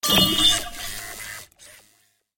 Звуки магии
Звук запутанной магической неудачи